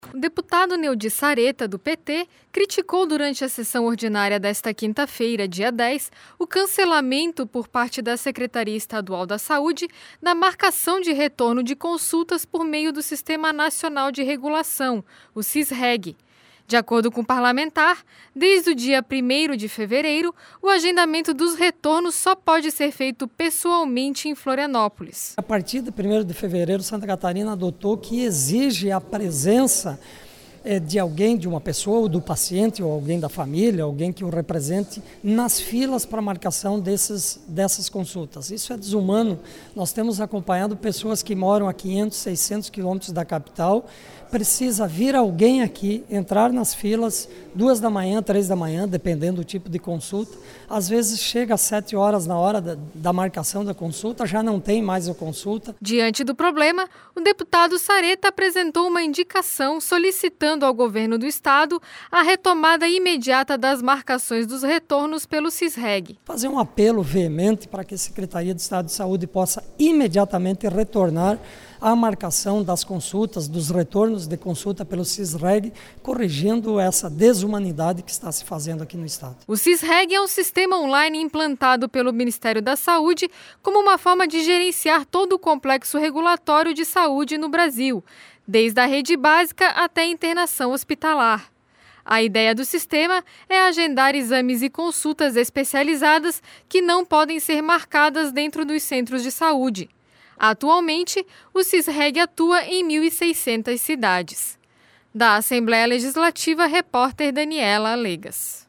Entrevista: Deputado Neodi Saretta (PT).